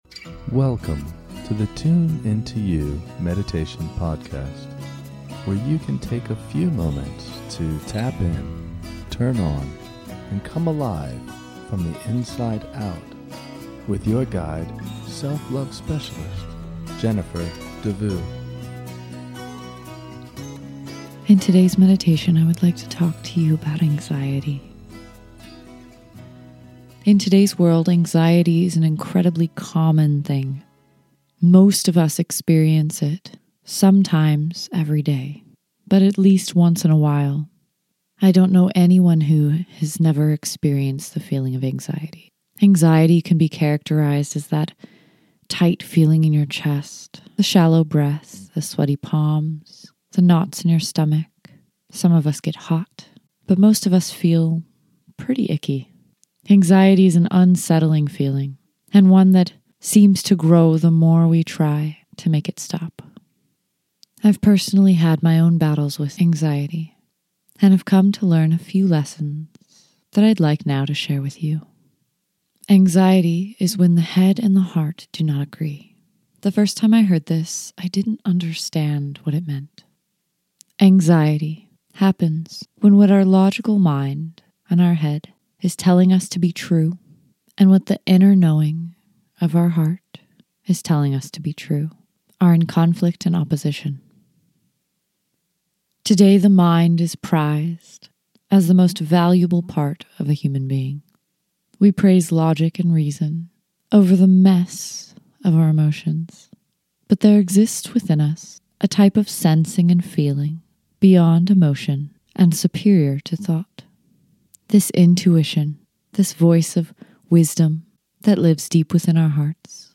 In this short guided meditation, you will get a chance to understand the source of your anxiety and hear the message your inner guidance has been trying to send you. You can come back to this meditation and this technique as often as you like to reduce anxiety in everyday life.